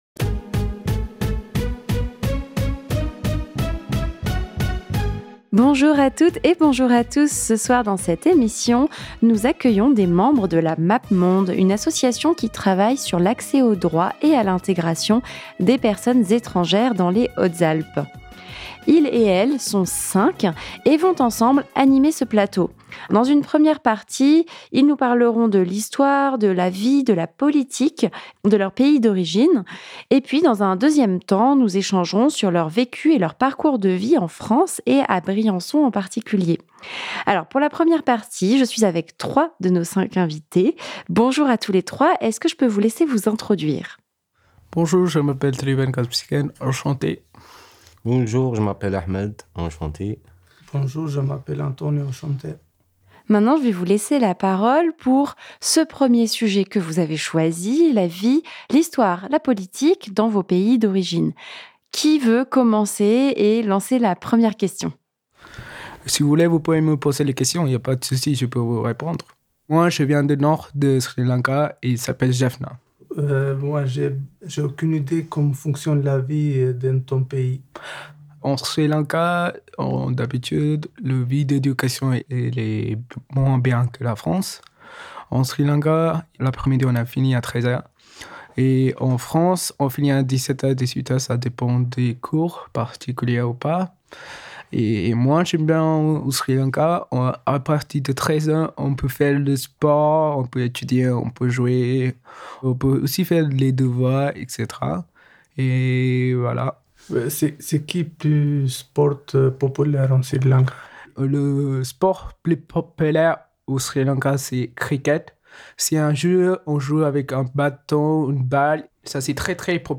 Ce temps d'échange et de discussion libre leur a été proposé dans le cadre d'un atelier radio à Fréquence Mistral Briançon.